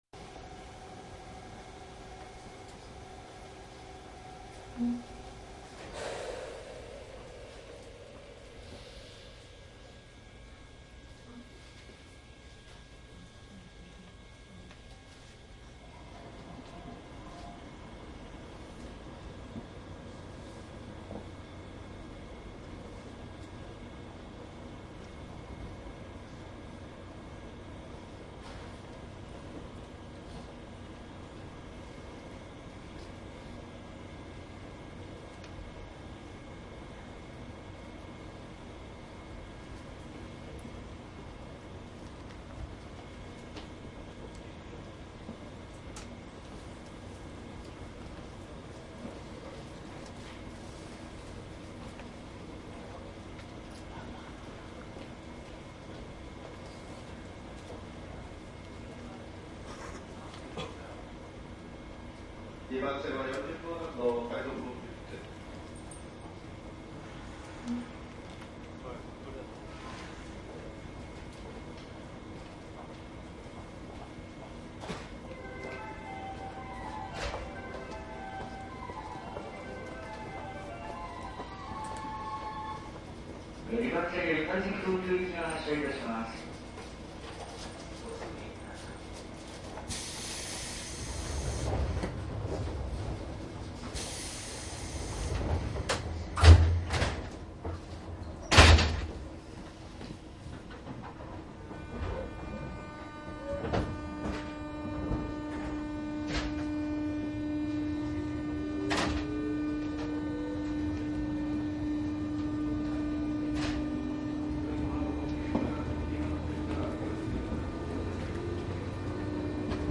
JR青梅線上り201走行音CD
山岳区間はトンネル、カーブ有で聴きどころがありますが、複線区間は駅間も短くダラダラ走り拝島～立川では客も多少増えます。
サンプル音声 モハ２０１-75.mp3
いずれもマイクECM959です。TCD100の通常SPモードで録音。
実際に乗客が居る車内で録音しています。貸切ではありませんので乗客の会話やが全くないわけではありません。